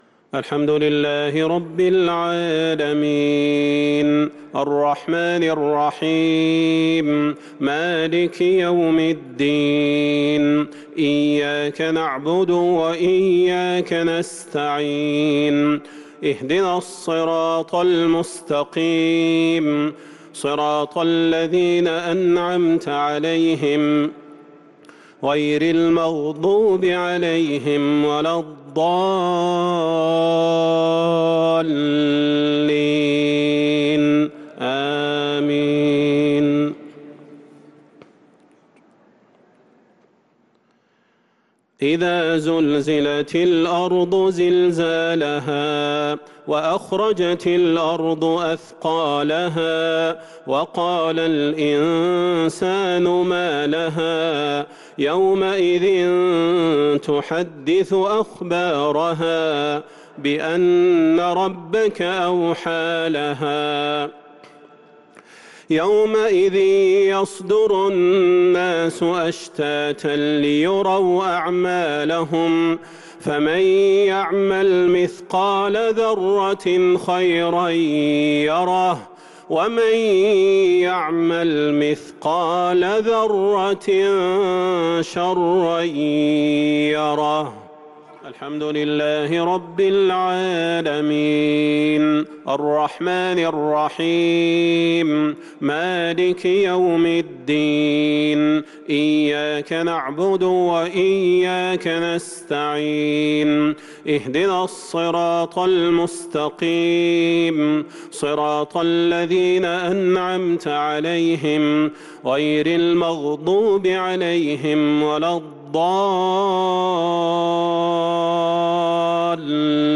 عشاء السبت 1 رمضان 1443هـ سورتي الزلزلة و القارعة | isha prayer from Surat Az-Zalzalah & Al-Qariah 2-4-2022 > 1443 🕌 > الفروض - تلاوات الحرمين